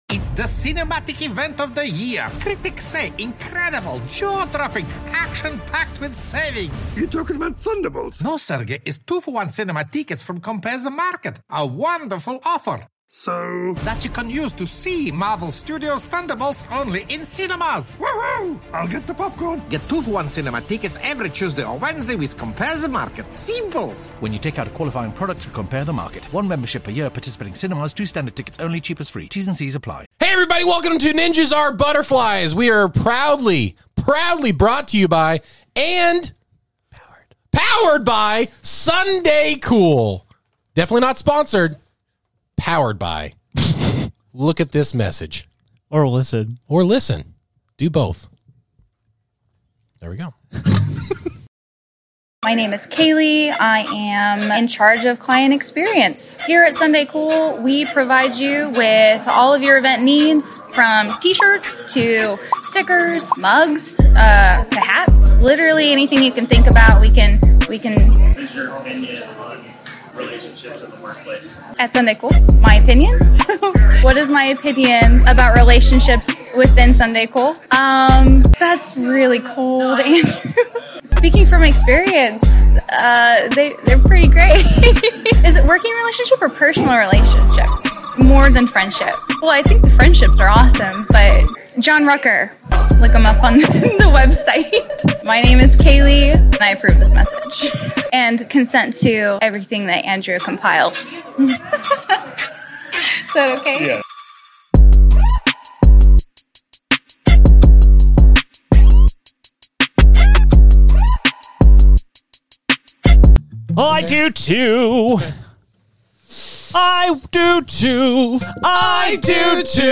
Later on, we'll update you on the latest news regarding the dead fish, Mr. Noodle, and discuss whos truly at fault for the disposal of his remains. With a brand new Emo song to open the show and opening fan mail to end it, there's no doubt this episode will stand out amongst the rest.